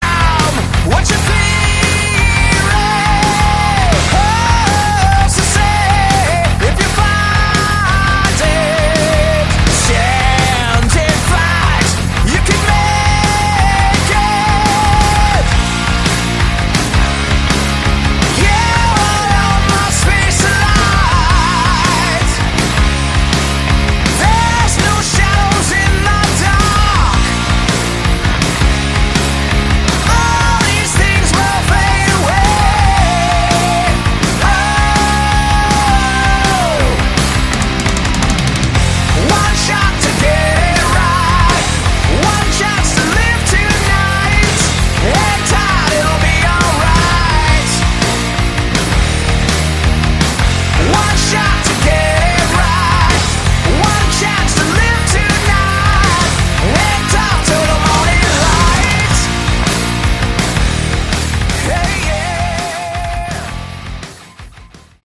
Category: Hard Rock
vocals
guitar
bass, vocals
drums, vocals